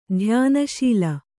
♪ dhyāna śila